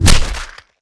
clawshit1.wav